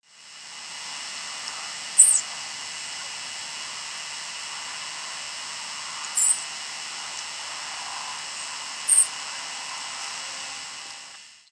Saltmarsh Sharp-tailed Sparrow diurnal flight calls
Diurnal calling sequences:
5. New Jersey October 23, 2001 (MO). Perched bird.